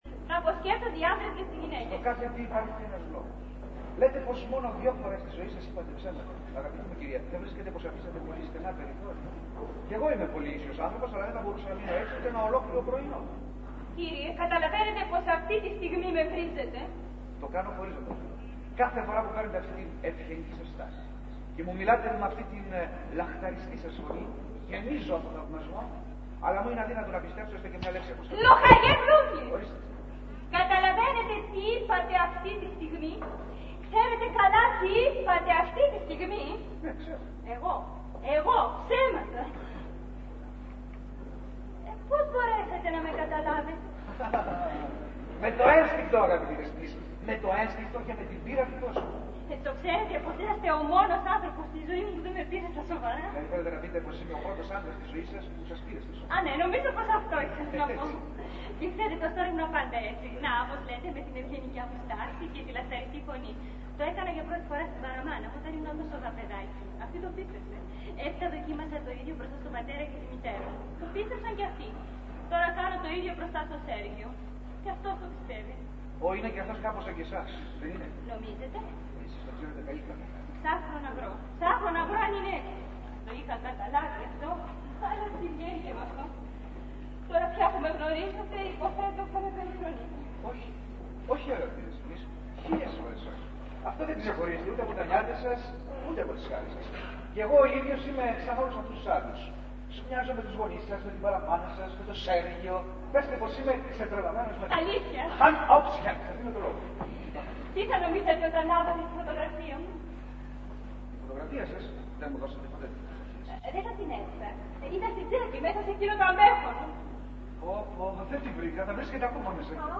Ηχογράφηση Παράστασης
Αποσπάσματα από την παράσταση
sound 2'52'', Μπλούντσλι-Ράινα